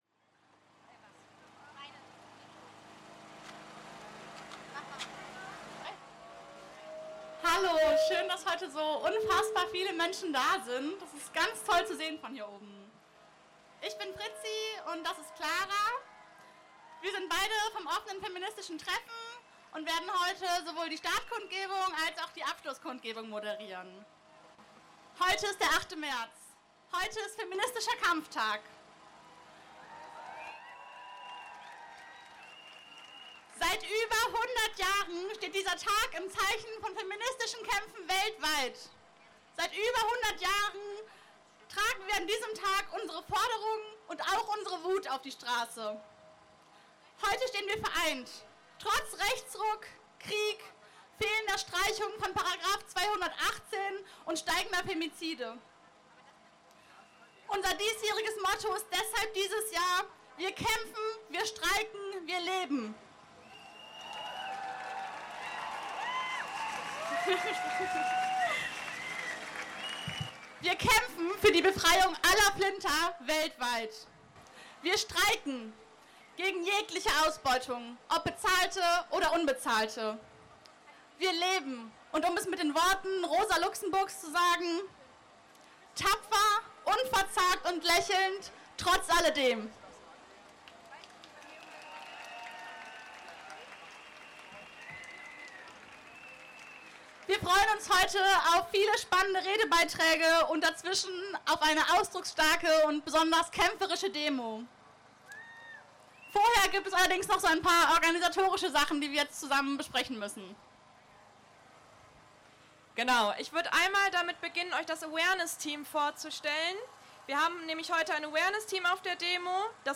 An diesem 8. März füllte sich der Kölner Neumarkt mit einer unüberschaubaren Zahl von Menschen, die für die Gleichberechtigung der Geschlechter auf die Straße gingen. Die Demo begann um 16:00 Uhr mit einer Kundgebung.